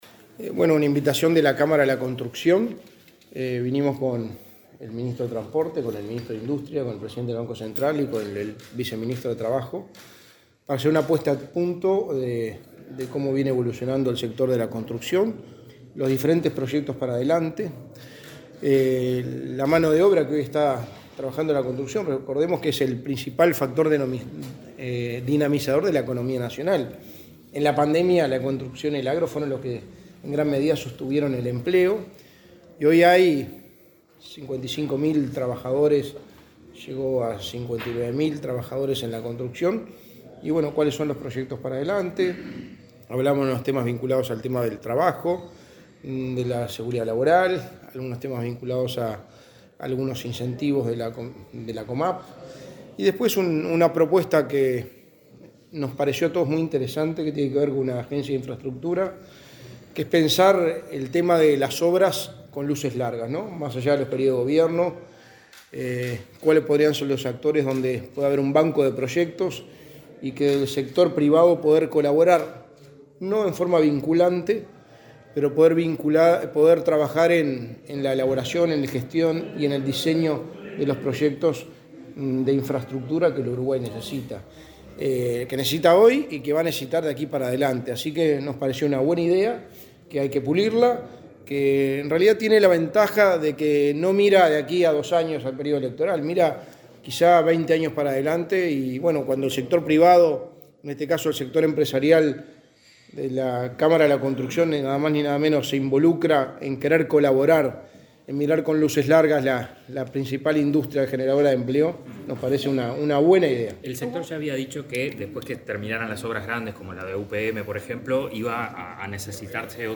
Declaraciones del secretario de Presidencia, Álvaro Delgado
El secretario de la Presidencia, Álvaro Delgado, dialogó con la prensa luego de participar en un almuerzo de trabajo con la Cámara de la Construcción.